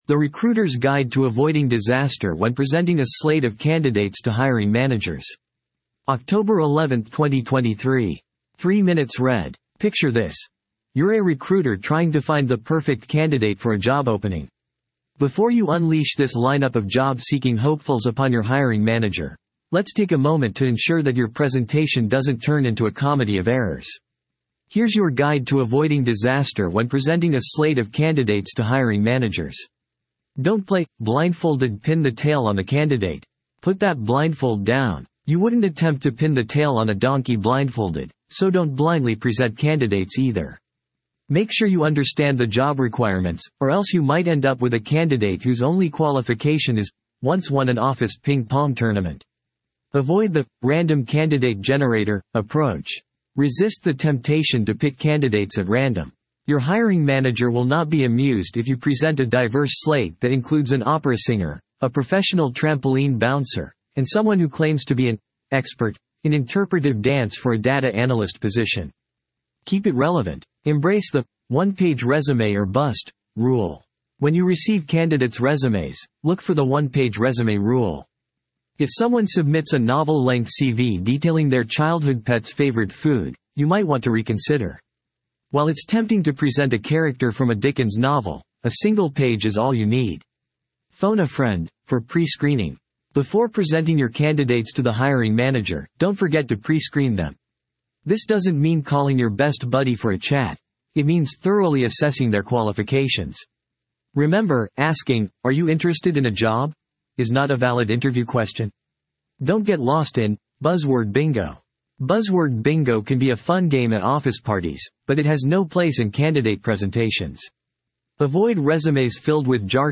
You can use this audio player to convert website page content into human-like speech. 11:11 00:00 / 14:00 1.0X 2.0X 1.75X 1.5X 1.25X 1.0X 0.75X 0.5X Picture this: You're a recruiter trying to find the perfect candidate for a job opening.